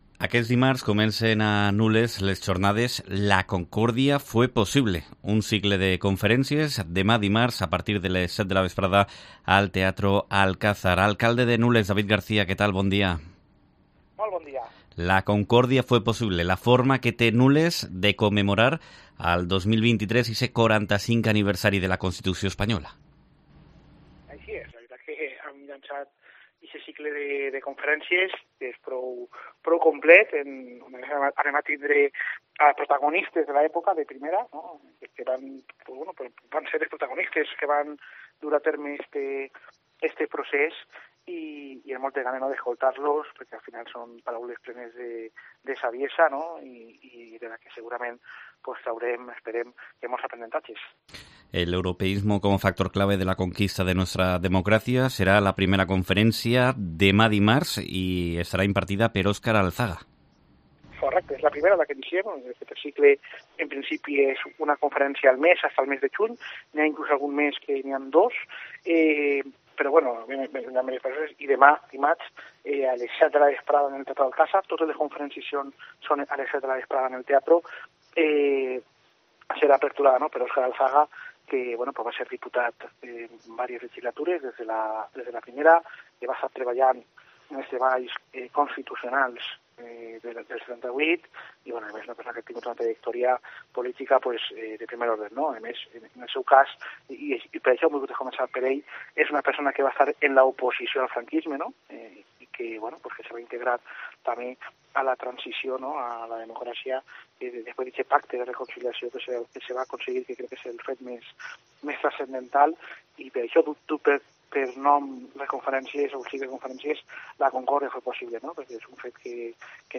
Entrevista
Nules conmemora el 45 aniversario de la Constitución Española con un ciclo de conferencias como explica a COPE el alcalde, David García